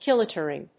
アクセント・音節kílo・lìter, 《主に英国で用いられる》 kílo・lìtre